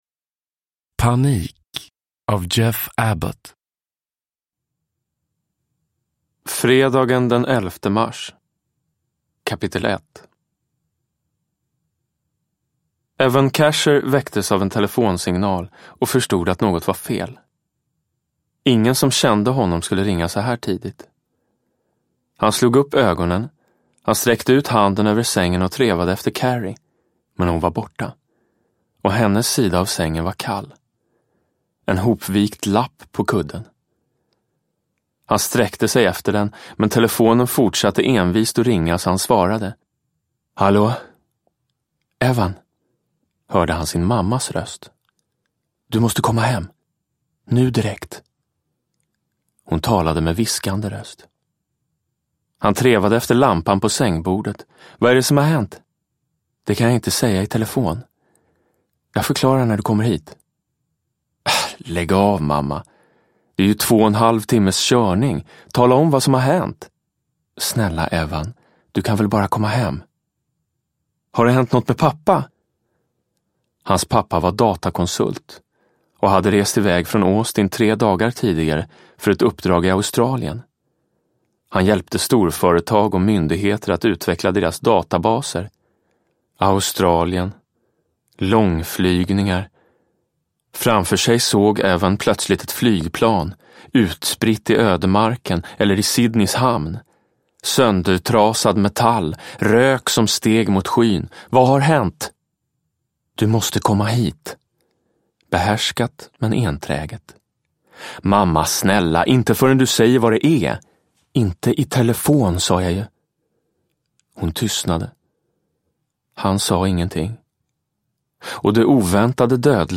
Panik – Ljudbok – Laddas ner
Uppläsare: Jonas Karlsson